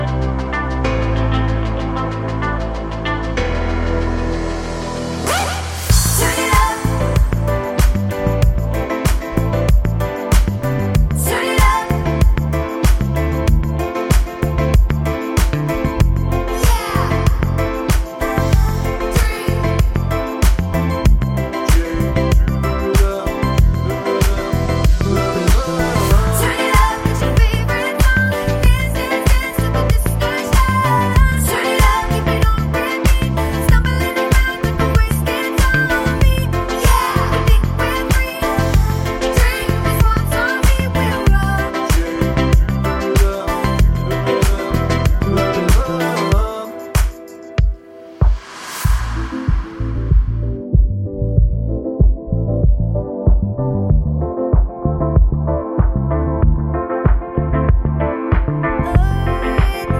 For Solo Female Pop (2010s) 3:57 Buy £1.50